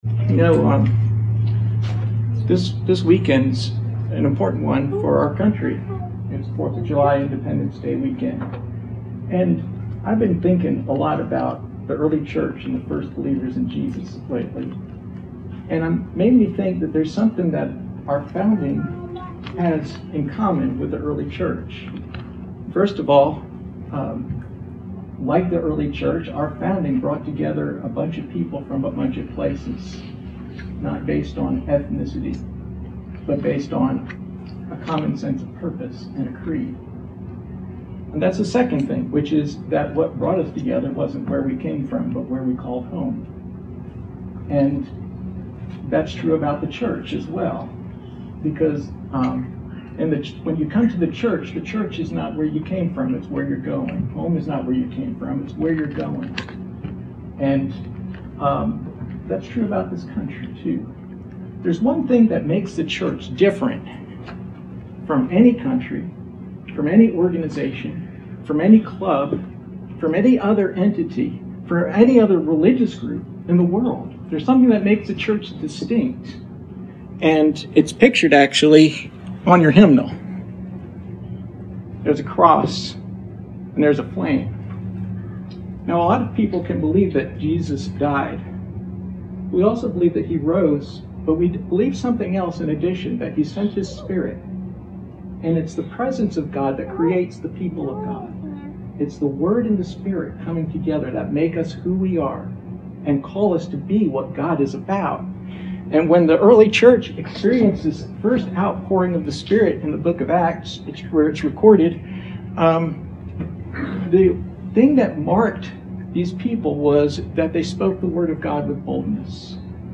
Passage: Acts 8 Service Type: Sunday Morning